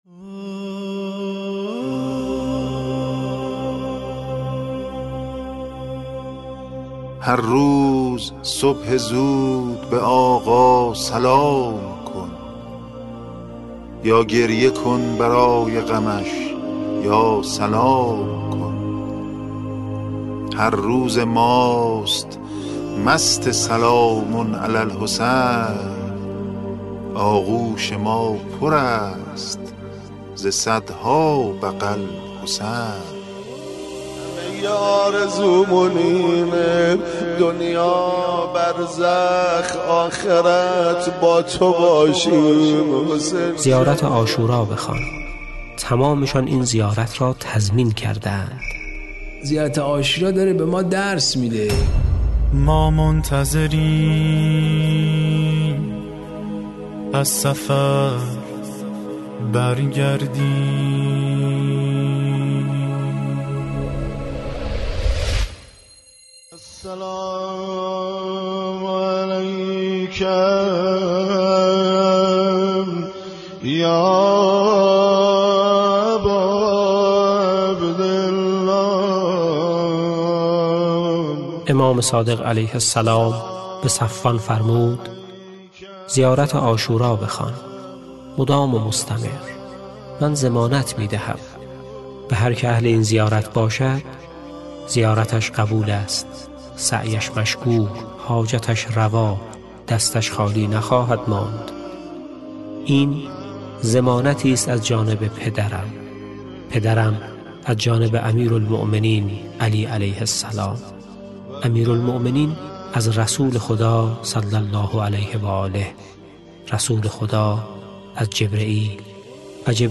روضه‌ی قدسی
• مناسبت: محرم